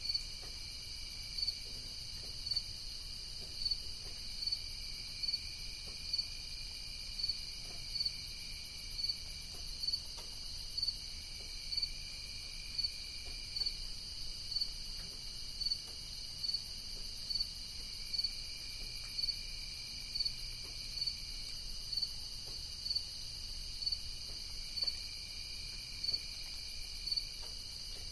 Crickets at 3am in Picton County